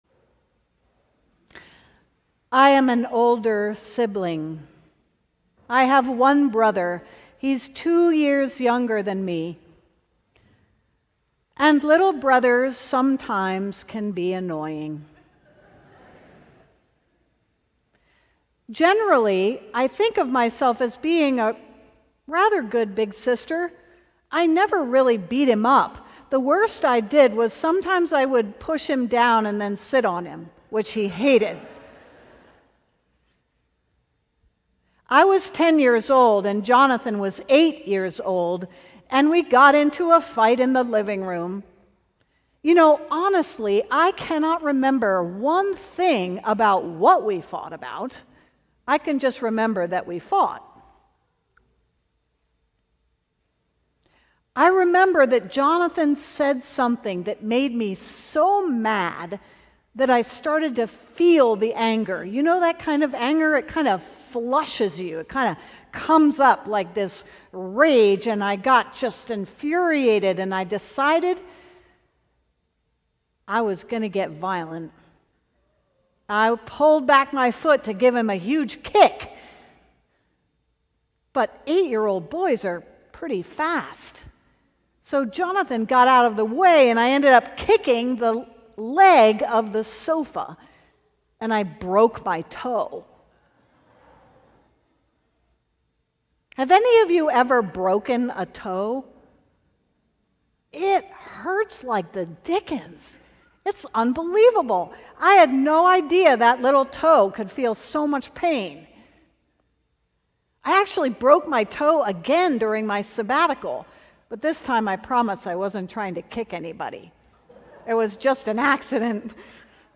Sermon: What to do with your pain - St. John's Cathedral